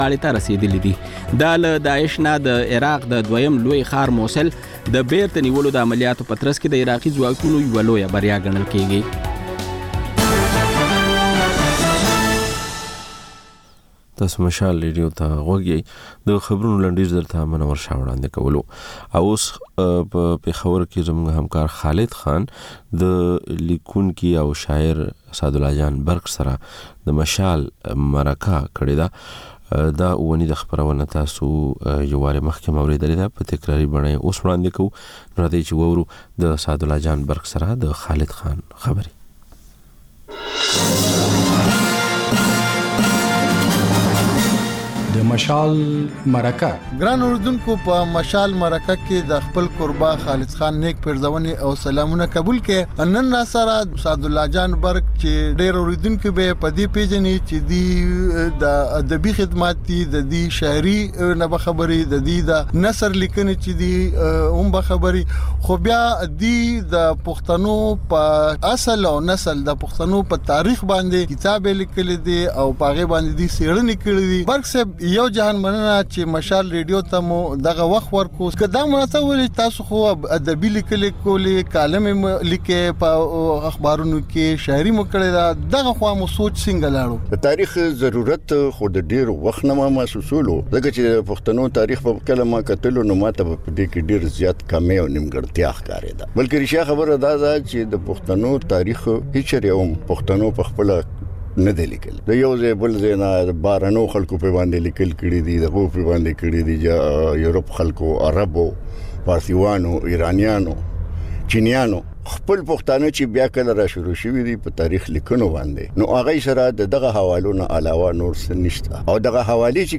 د مشال مرکه